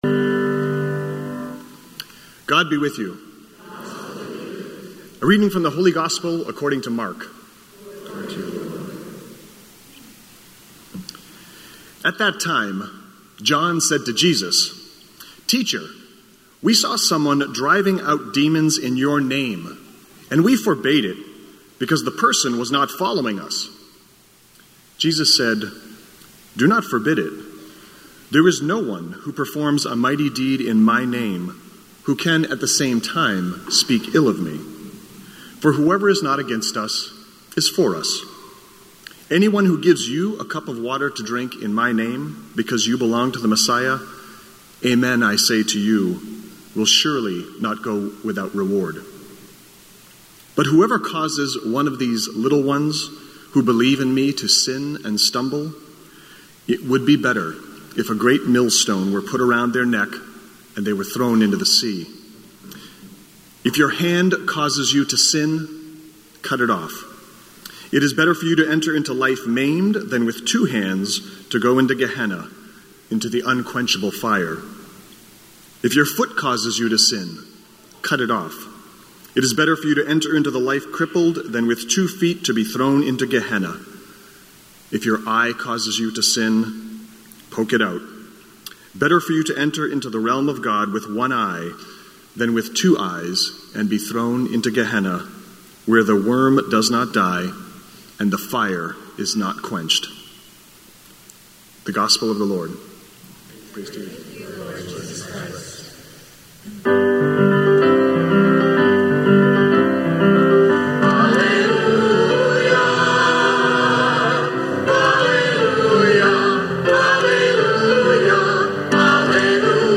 Spiritus Christi Mass September 30th, 2018